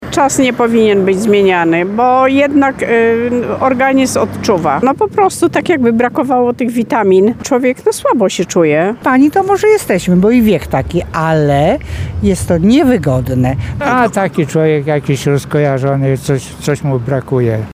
– To nie jest dobry pomysł, bo człowiek po prostu czuje się gorzej – mówią mieszkańcy Chełma.